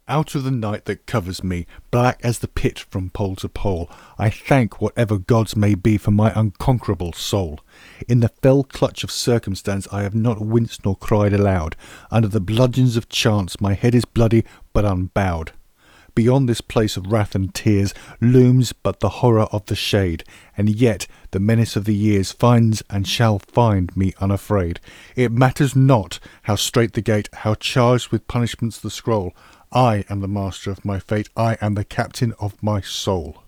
인빅투스 낭송